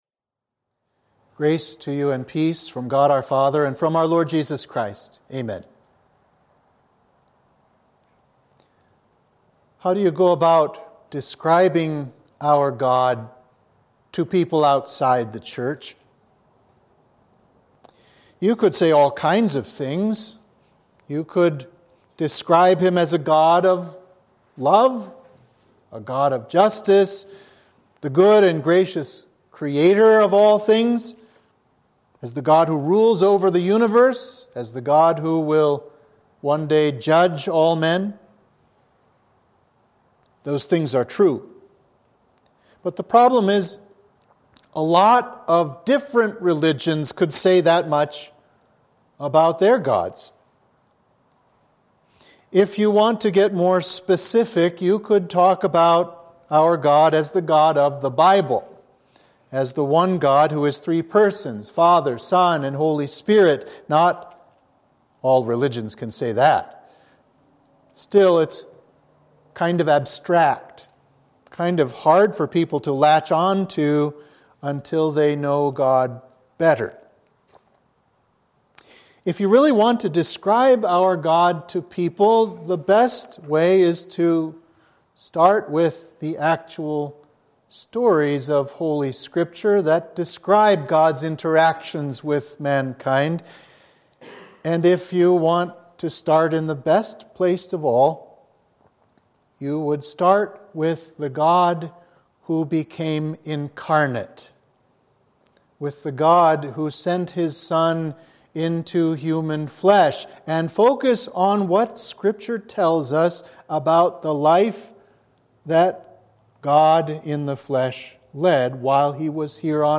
Sermon for Epiphany 2